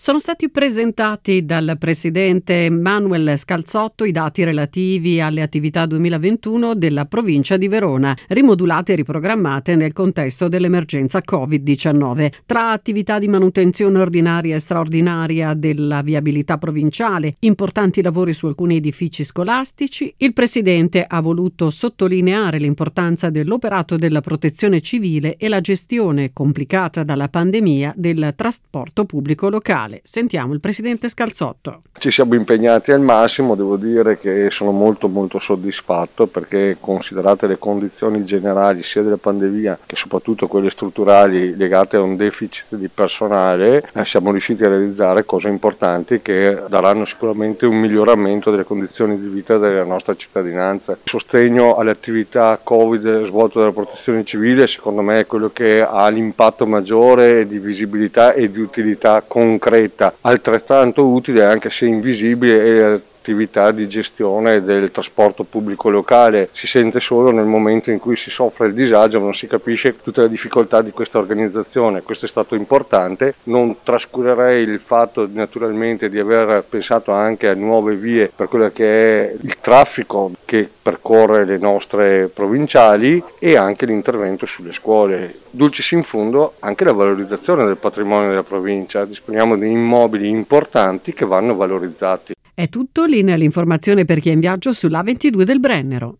31/12/2021: Presentati dal presidente della Provincia di Verona, Manuel Scalzotto, i dati relativi alle attività 2021 dell’ente: